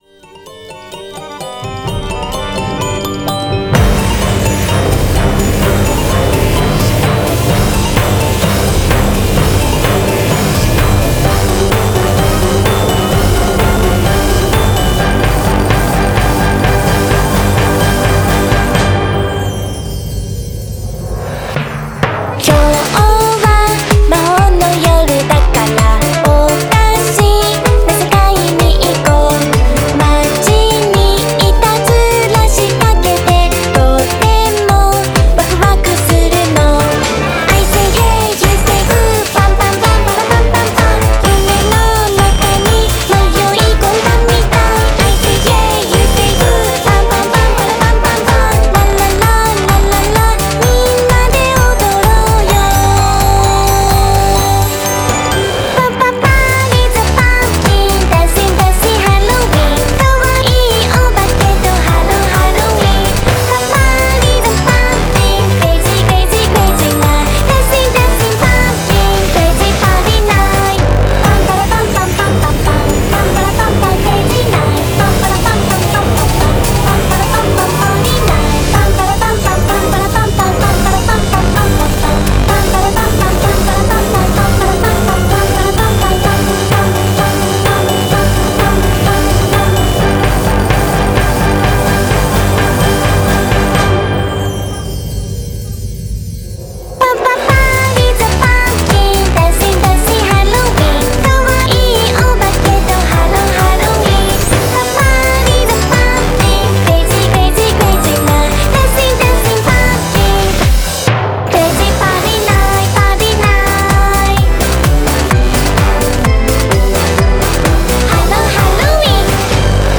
BPM128
Audio QualityPerfect (High Quality)